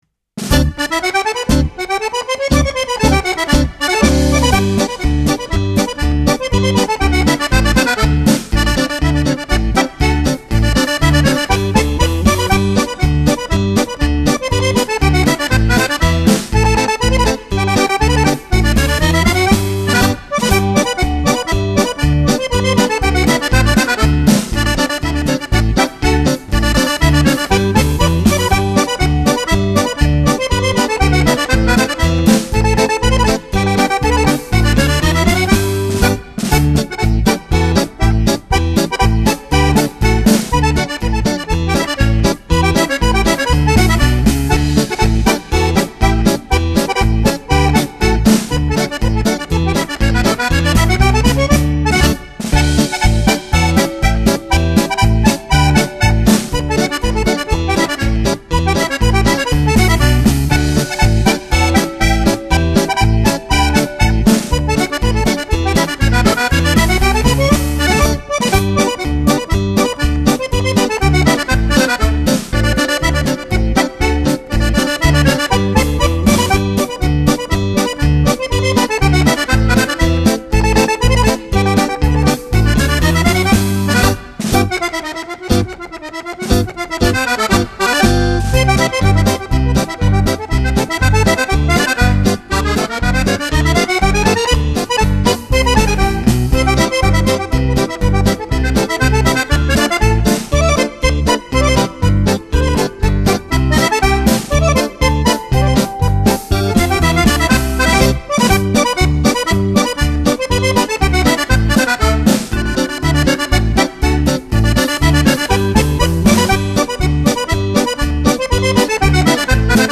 Genere: Polka